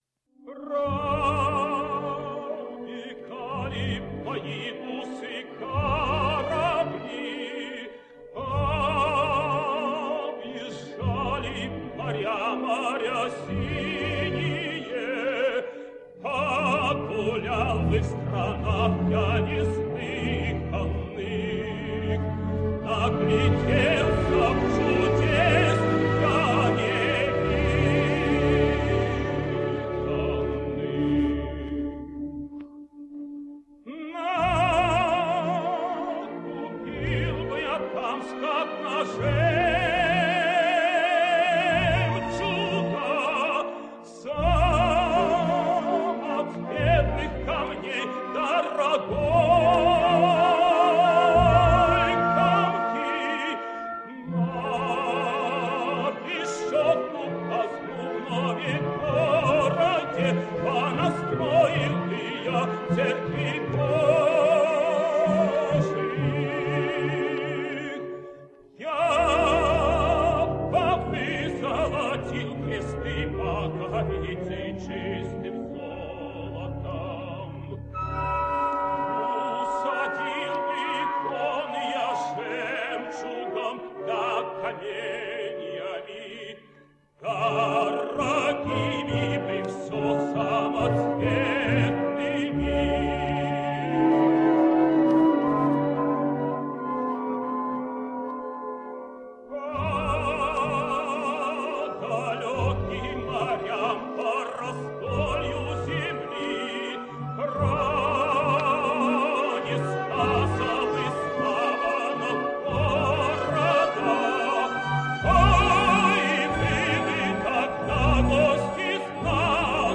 Ukrainian tenor.
Address to the city fathers / Sadco / 19xx – Georgi Nelepp